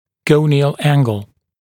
[‘gəunɪəl ‘æŋgl][‘гоуниˌон ‘энгл]гониальный угол (Ar-Go-Me)